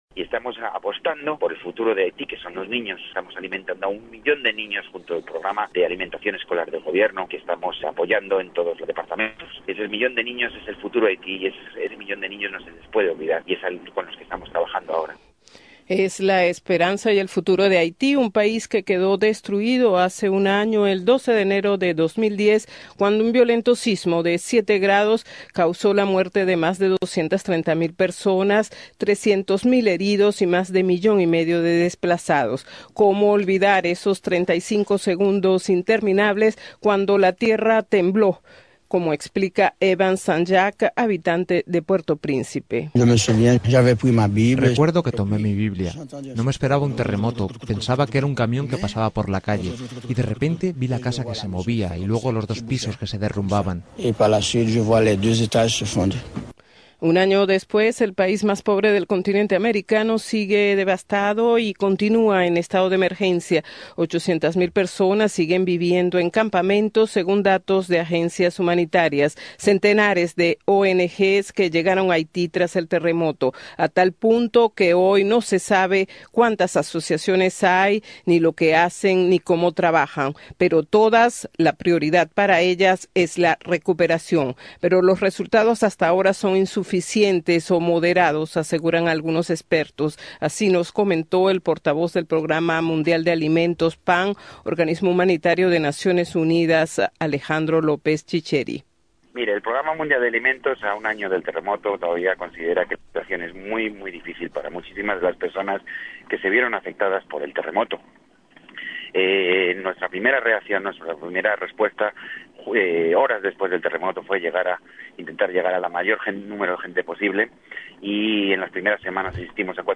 Luego del terremoto del 12 de enero de 2010, que dejó un saldo de 230.000 muertes, Haití lucha por reorganizarse. Escuche el informe de Radio Francia Internacional.